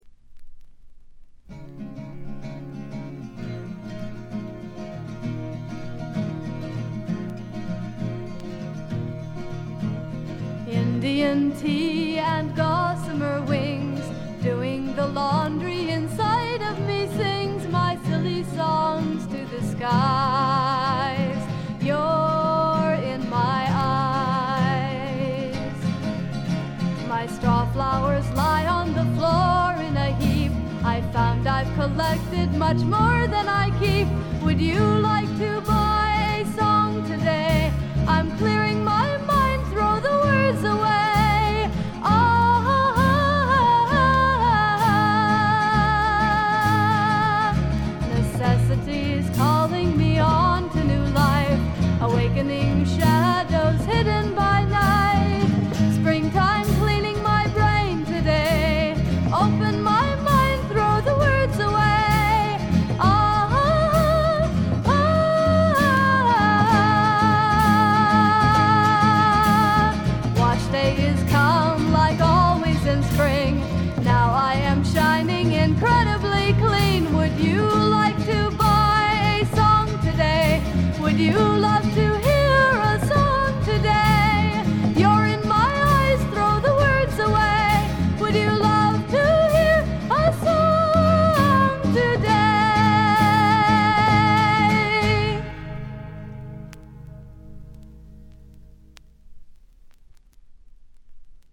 静音部での軽微なチリプチ程度。
甘酸っぱい香りが胸キュンのまばゆいばかりの青春フォークの傑作。
試聴曲は現品からの取り込み音源です。
Vocals, Guitar, Composed By ?